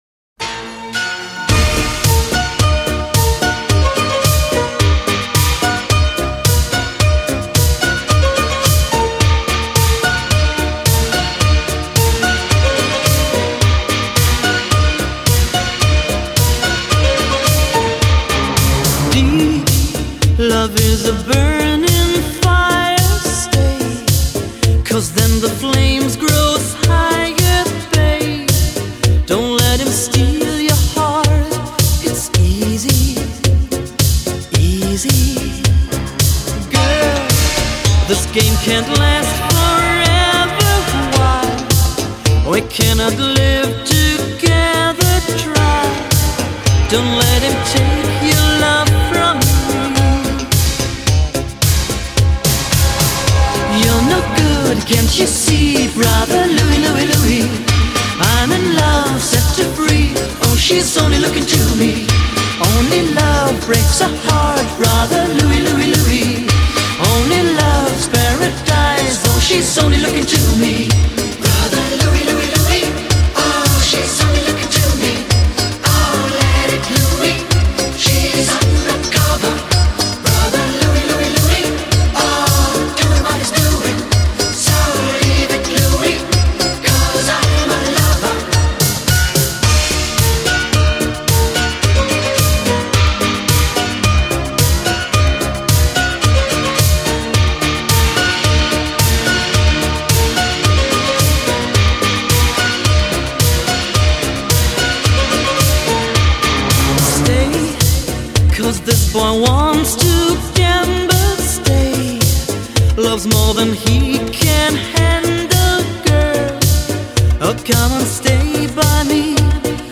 风靡80年代欧洲舞曲天王团体2010年最新精选；